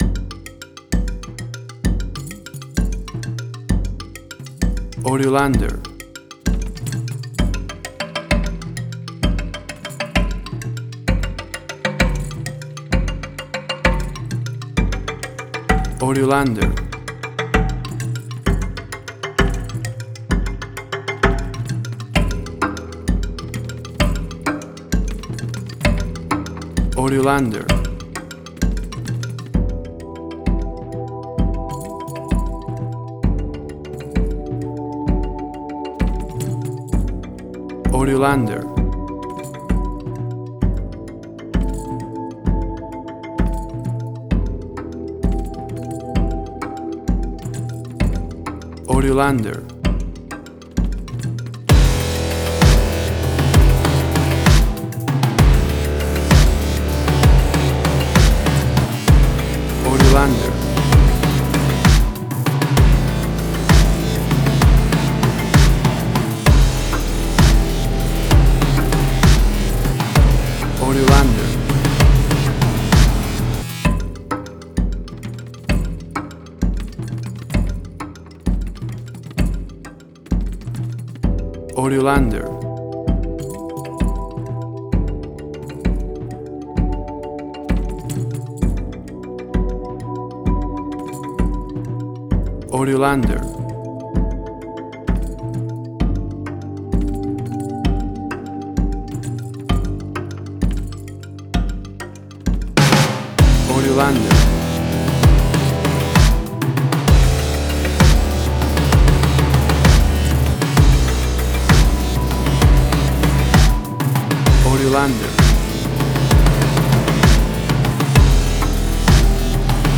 World Fusion_Similar_BBC Documentaries.
Tempo (BPM): 69